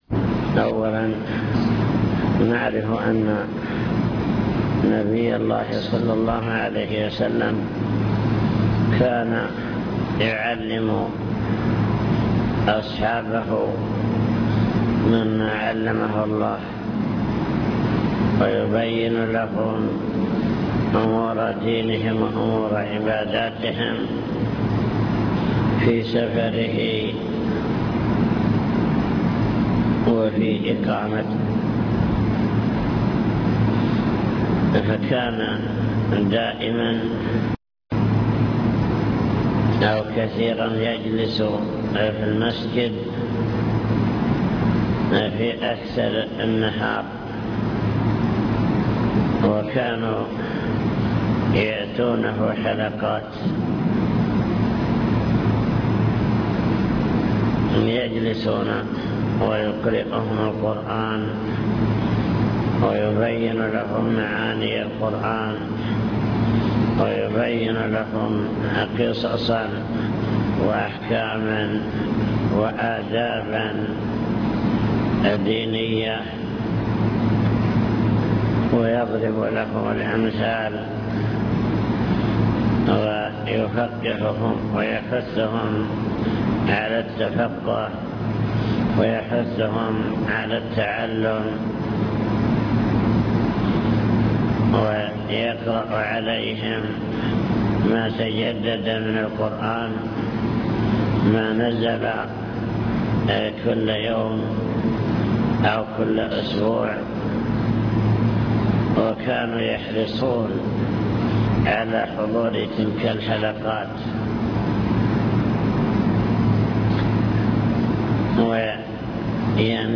المكتبة الصوتية  تسجيلات - محاضرات ودروس  محاضرات بعنوان: عناية السلف بالحديث الشريف عناية الصحابة بالحديث